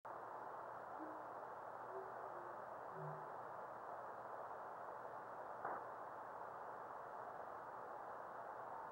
Observer's Comments:  Small fireball and a quick "bloop"-type sound reflection in movie 2 below.